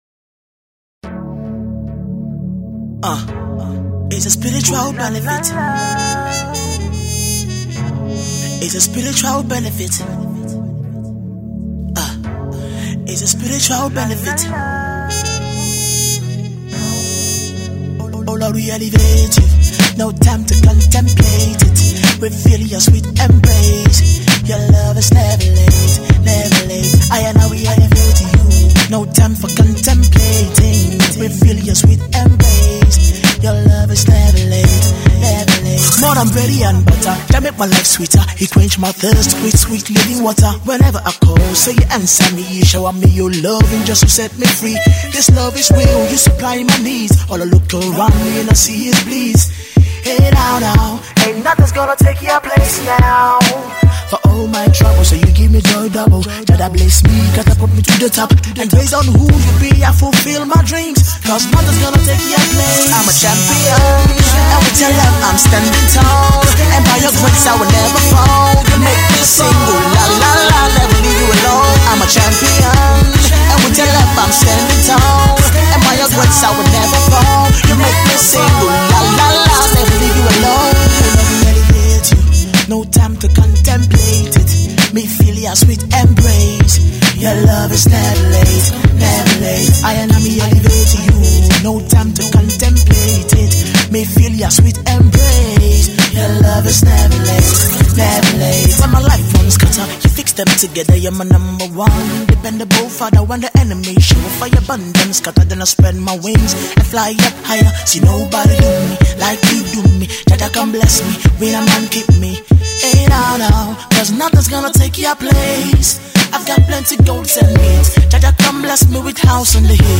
expresses elements of Reggae, Jazz and Afro Pop